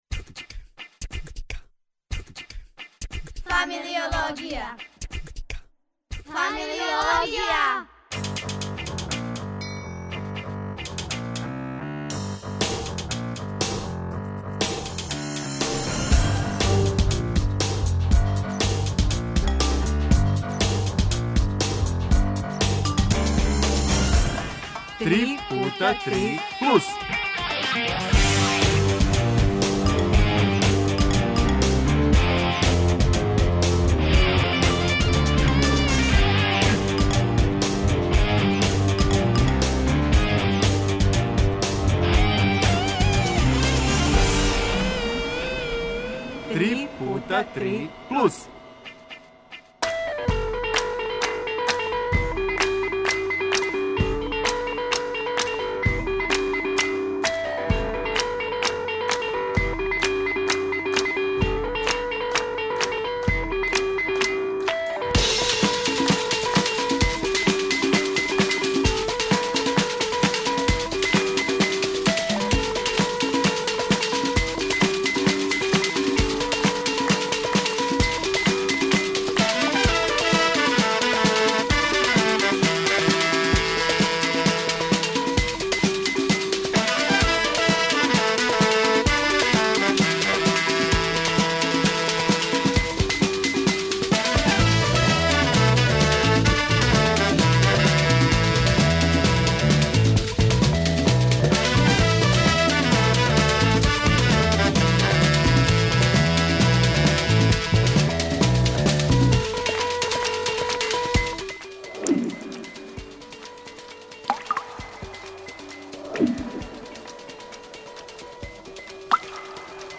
станд - уп комичари...